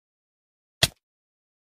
Golpe Minecraft. Téléchargement d'Effet Sonore
Golpe Minecraft.